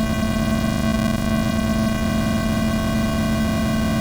noise_pitch_bad.wav